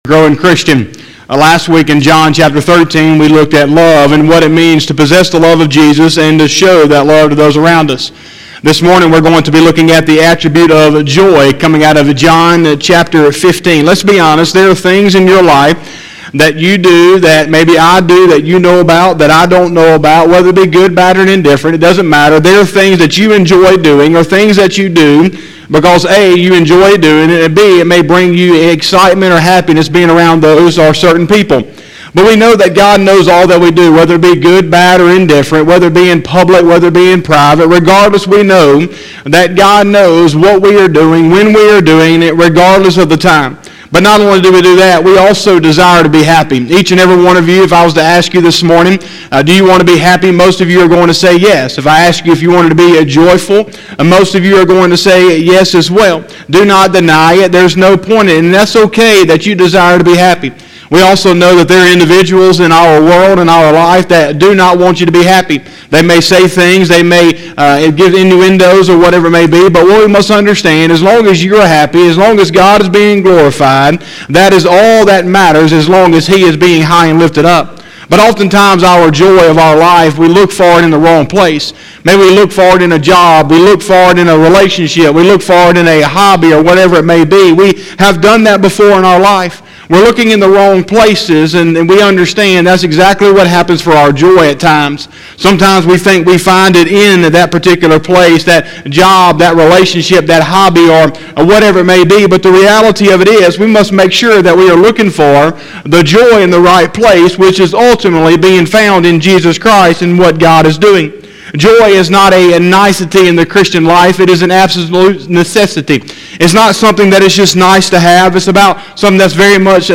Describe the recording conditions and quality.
01/10/2021 – Sunday Morning Service